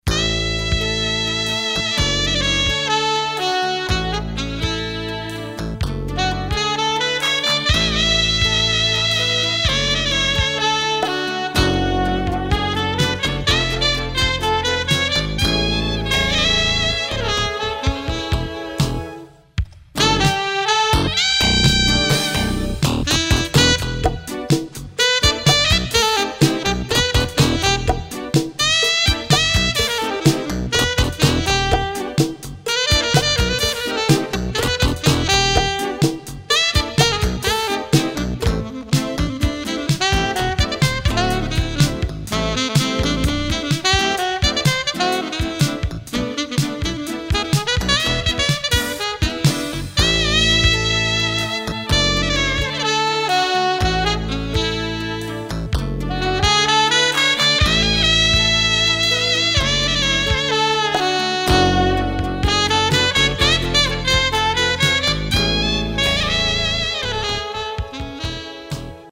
sax
keys\drums\bass Recorded on 4 track tape